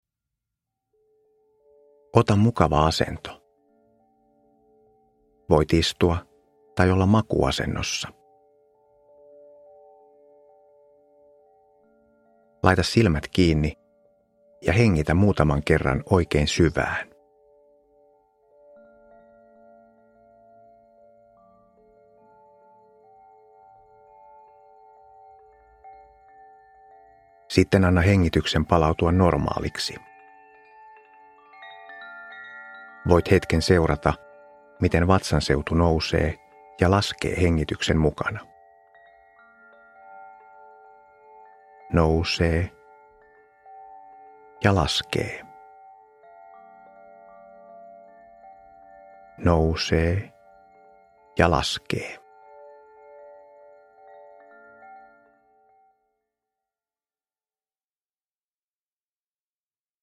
Kehorentoutusmeditaatio 10 min – Ljudbok – Laddas ner